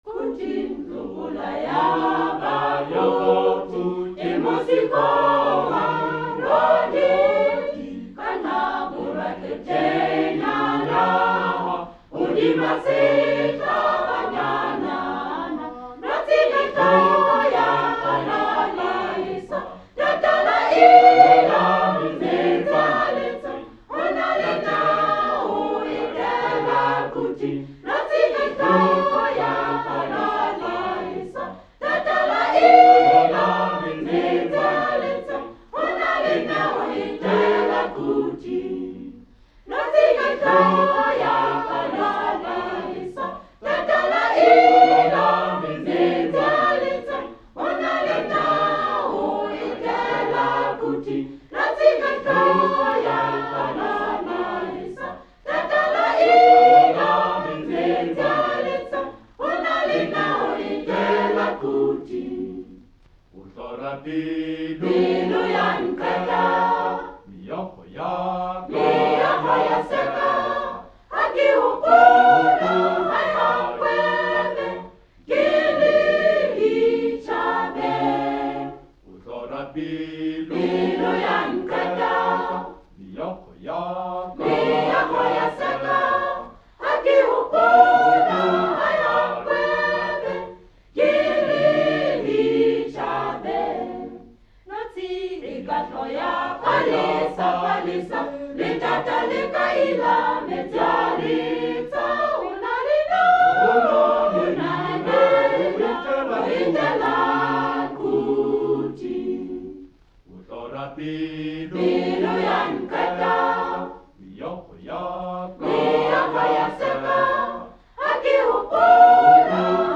choir SATB
Studio Recording